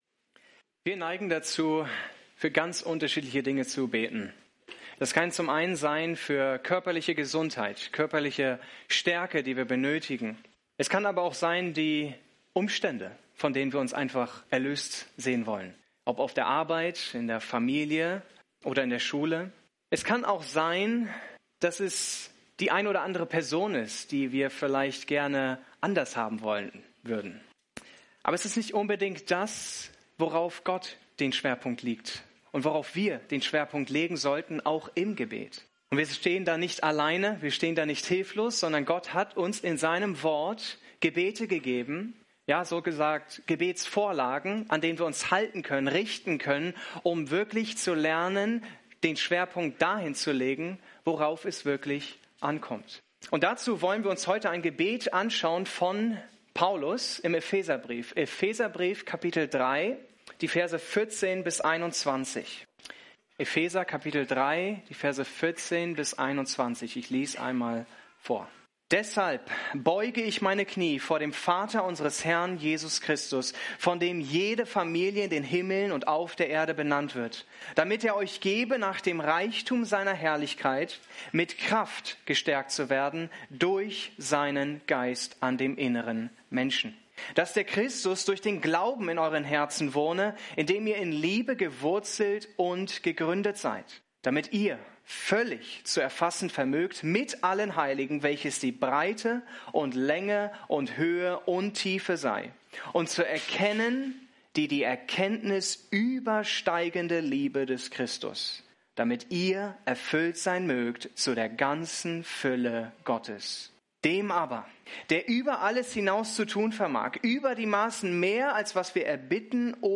Predigten der Eckstein Gemeinde
Schön, dass Du unsere Predigt hörst.